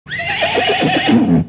Catégorie Animaux